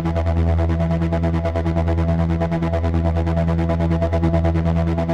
Index of /musicradar/dystopian-drone-samples/Tempo Loops/140bpm
DD_TempoDroneA_140-E.wav